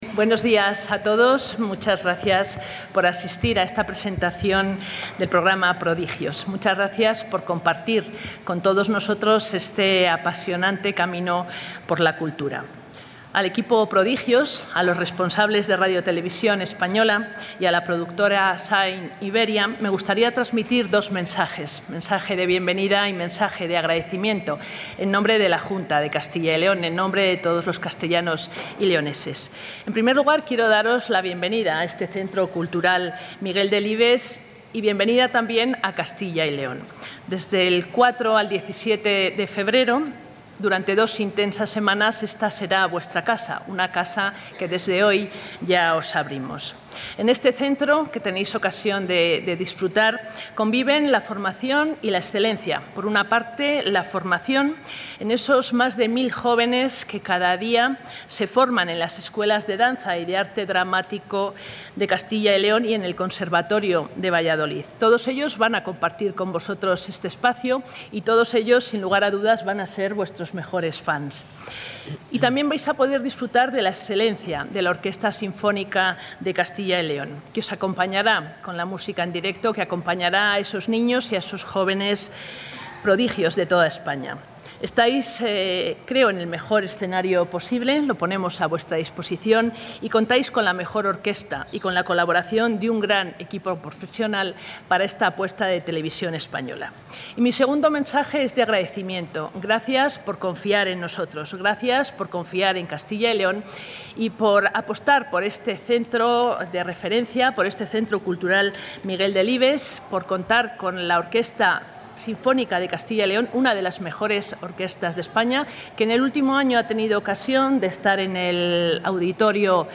Audio consejera.